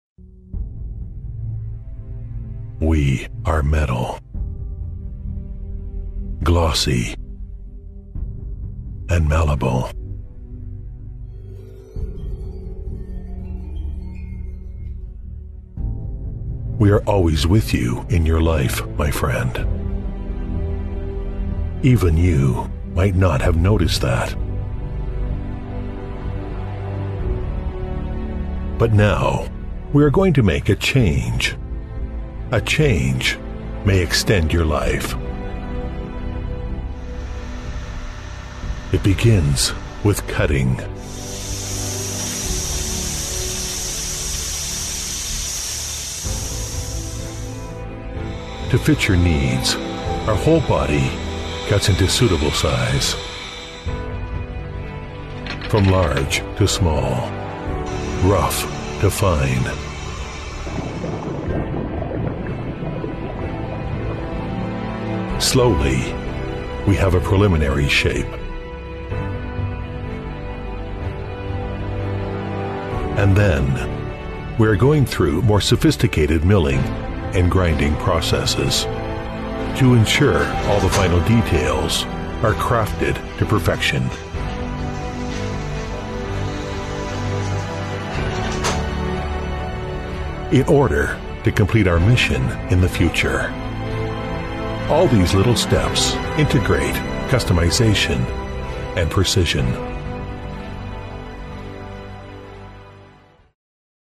• T110-1 美式英语 男声 艾迪尔(广告) 激情激昂|大气浑厚磁性|沉稳|低沉|娓娓道来